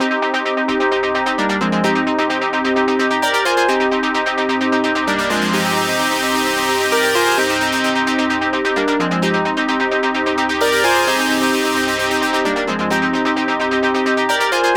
FILTER BED 1.wav